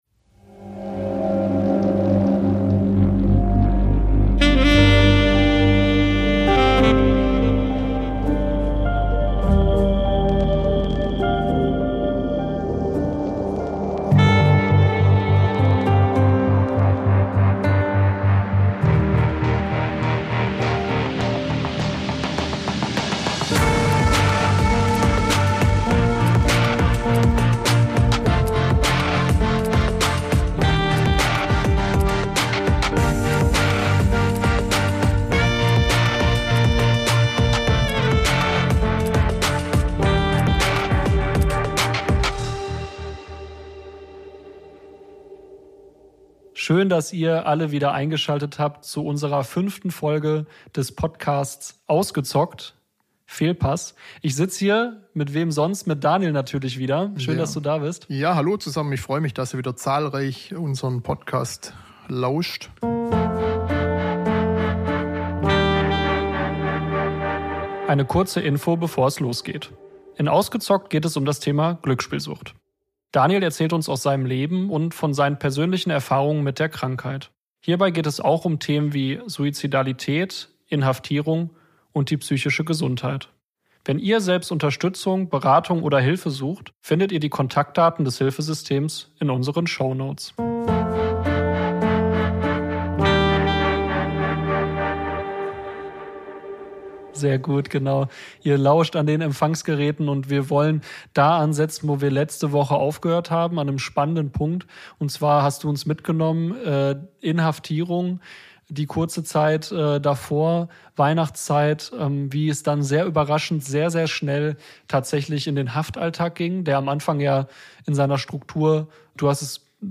Folge 5: Neue Pfade ~ Ausgezockt: Sucht ungefiltert - Betroffene im Gespräch Podcast